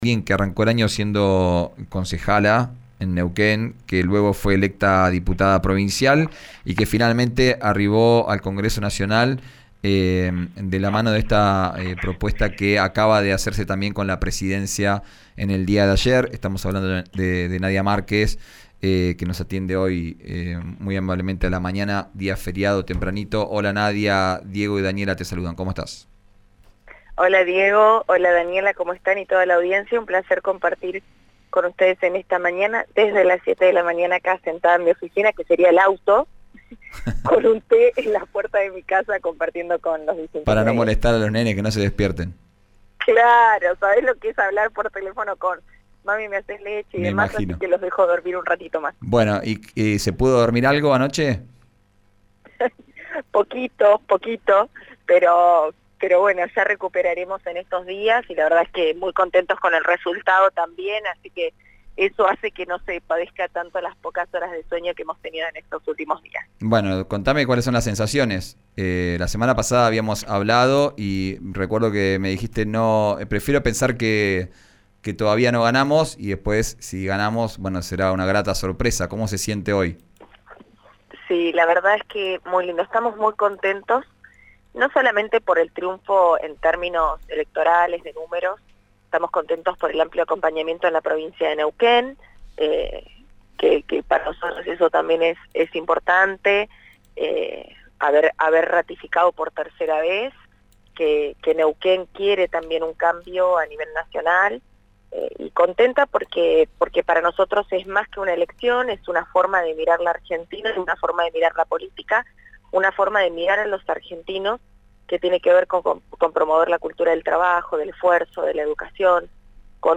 En diálogo con RÍO NEGRO RADIO, Márquez le quitó relieve a los actos de Sergio Massa en Plottier y Cipolletti: «No nos parecía que eran acciones que iban a volcar el voto hacia él, había gente que me llamaba y me decía, Nadia, nos obligan a ir desde el sindicato pero no lo vamos a votar«.
Escuchá a la diputada nacional Nadia Márquez en RÍO NEGRO RADIO: